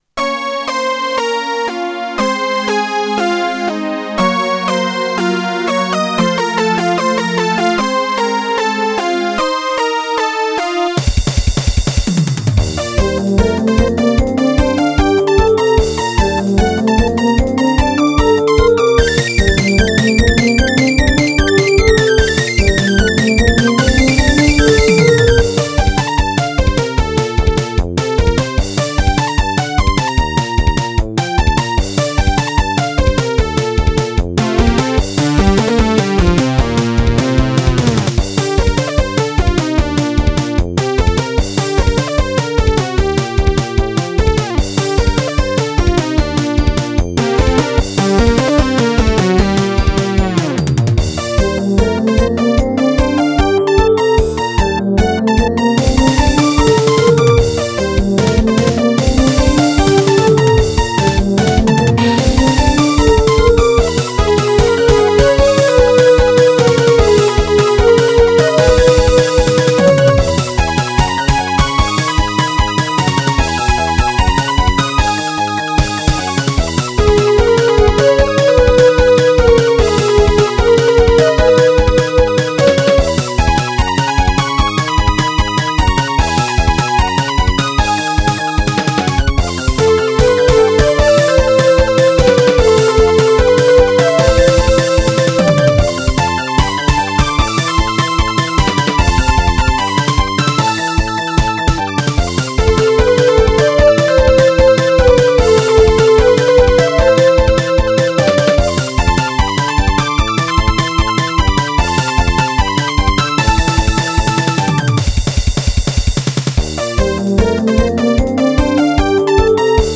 (UM-4→SC-88→ライン入力で録音しております)
3、4トラック程度しか入れてないのでだいぶ寂しいことになってます。